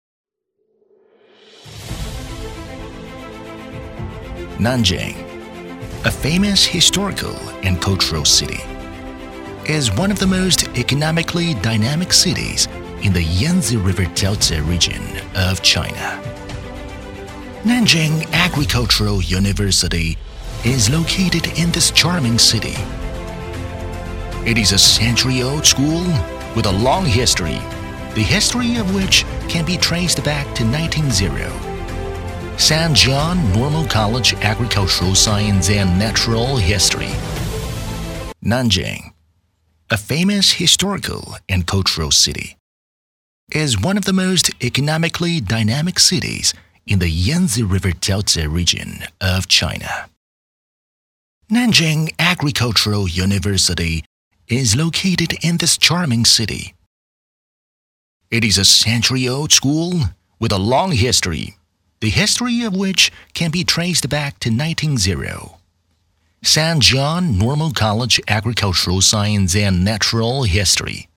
【专题】美式 专题 年轻音色 大气2
【专题】美式 专题 年轻音色 大气2.mp3